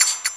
Perc (1).wav